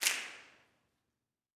SNAPS 26.wav